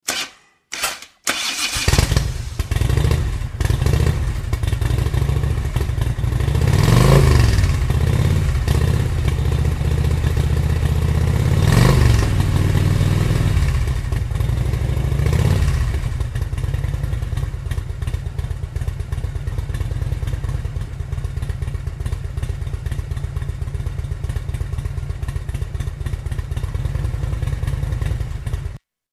Harley-Davidson - Харли-Дэвидсон
Отличного качества, без посторонних шумов.